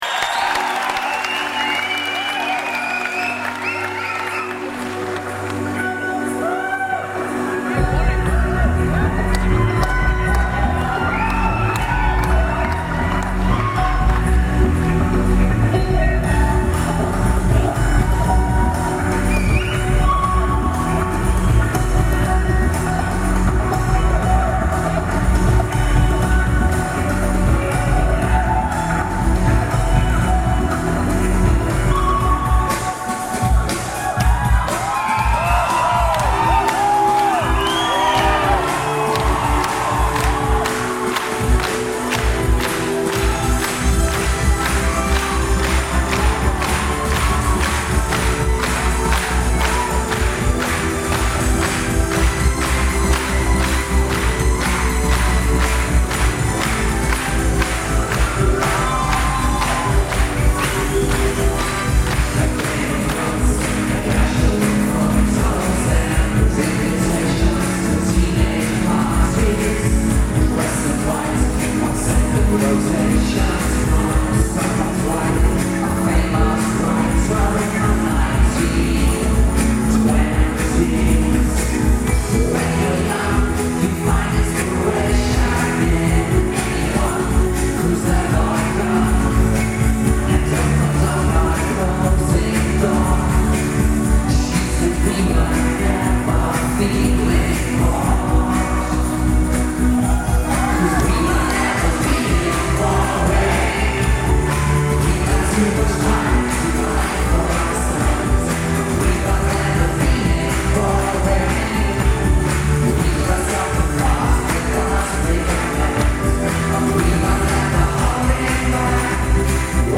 23rd June, 2002 Leipzig Germany
(Medium quality,
recorded from audience, 8 MB)